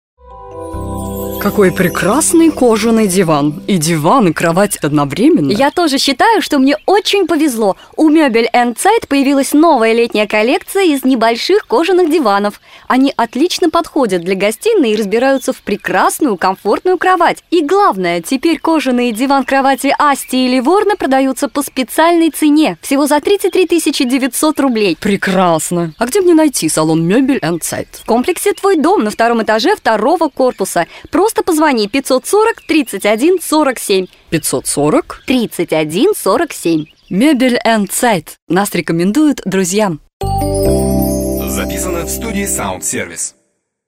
1) Аудиоролик «Диалог» - это беседа диктора с другим человеком (или людьми) или беседа двух и более человек без диктора.
Скажем, одна домохозяйка делится мнением с другой.